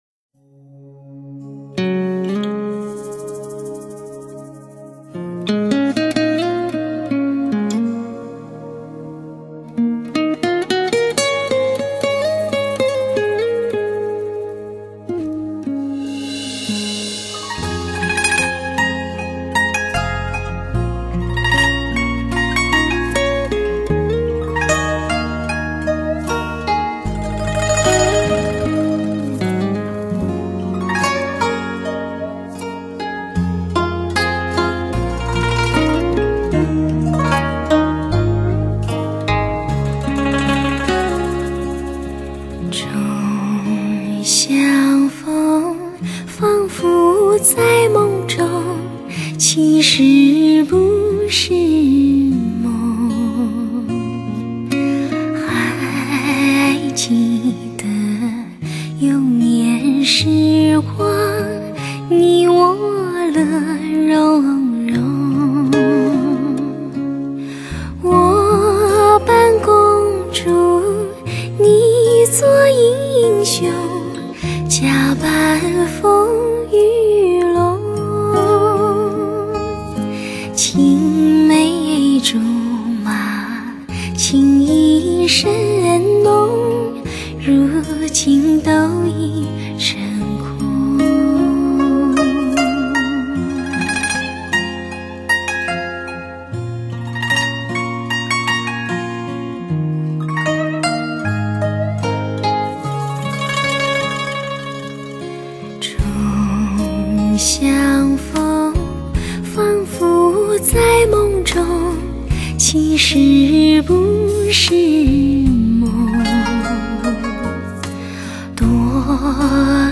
听觉效果丰富多变，令你一听难忘！